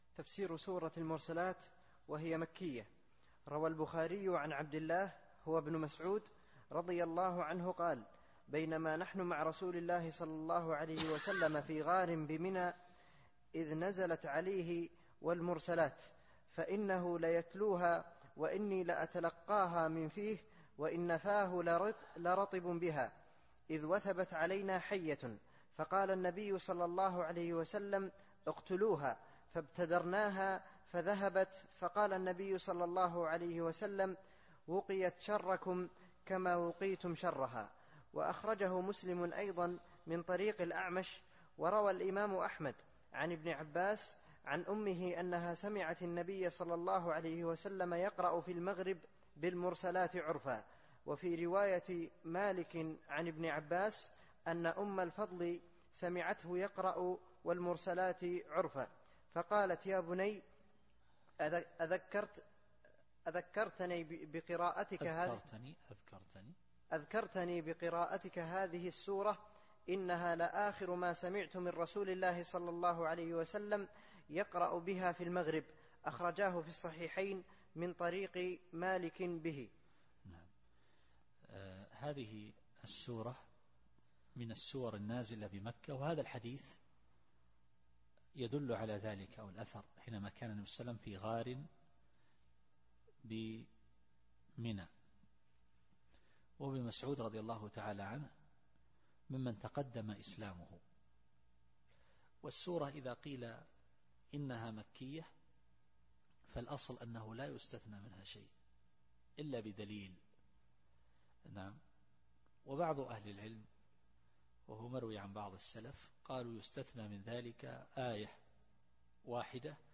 التفسير الصوتي [المرسلات / 3]